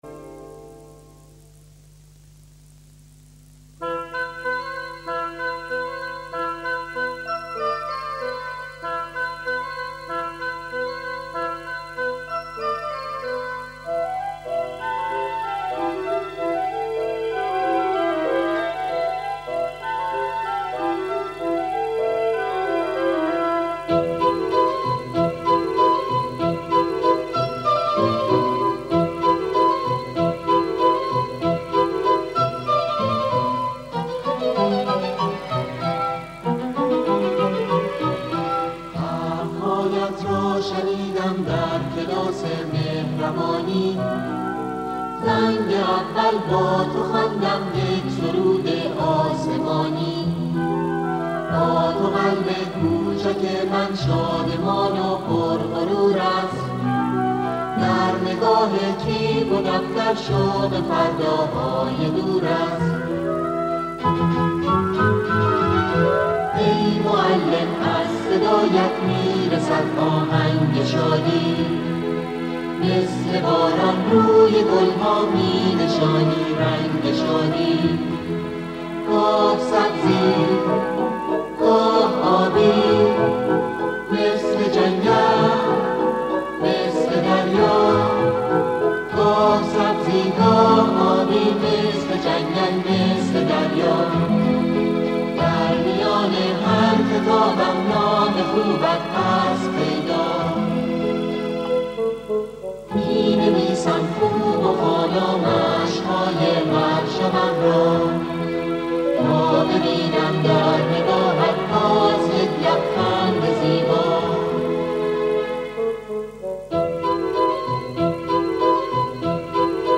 سرودهای روز معلم